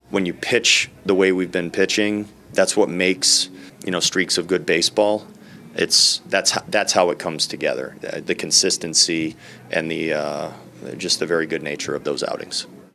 Manager Rocco Baldelli says pitching has fueled the winning streak.